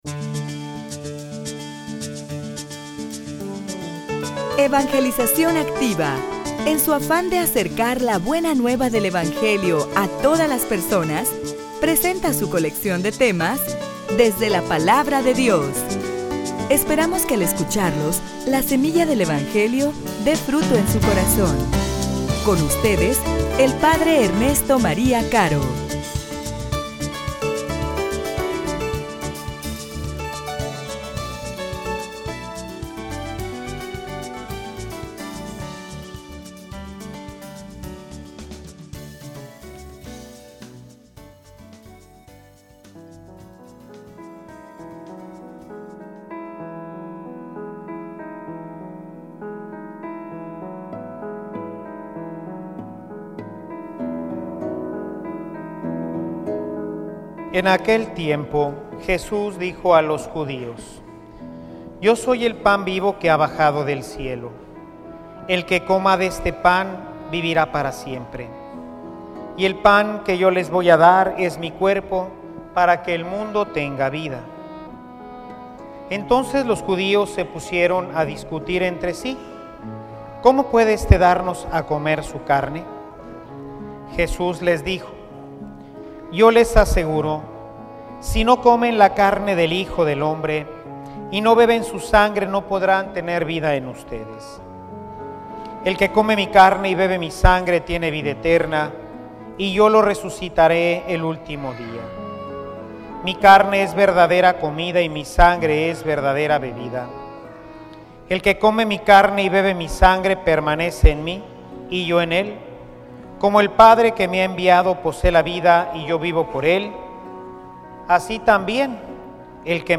homilia_Lo_que_produce_el_Pan_de_Vida.mp3